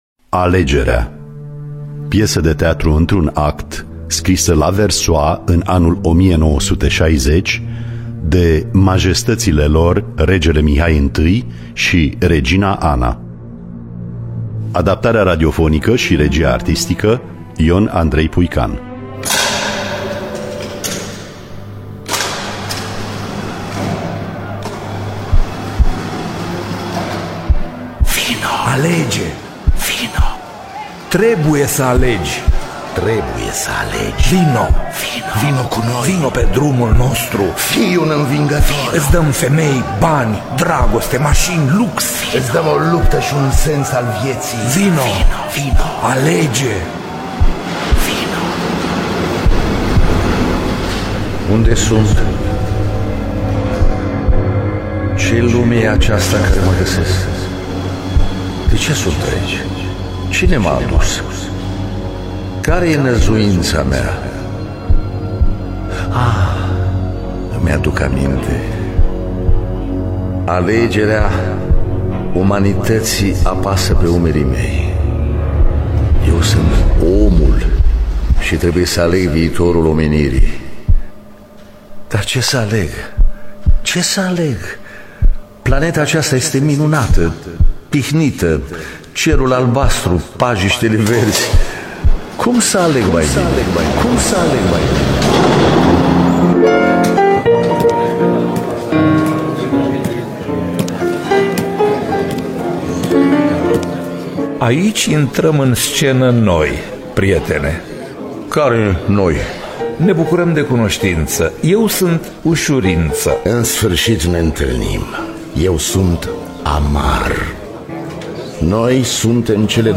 Mihai I de Hohenzollern-Sigmaringen si Ana de Bourbon-Parma – Alegerea (2016) – Teatru Radiofonic Online